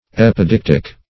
Epideictic \Ep`i*deic"tic\, a. [Gr.